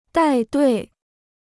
带队 (dài duì) Free Chinese Dictionary